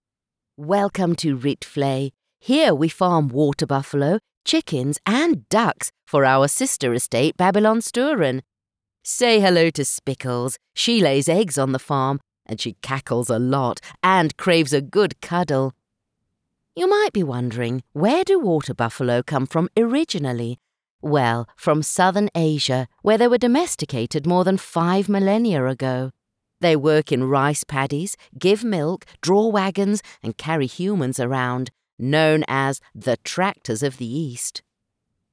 Anglais (sud-africain)
Audioguides
Ma voix est naturelle et chaleureuse, tout en étant résonnante et autoritaire.
Micro Audio Technica AT2020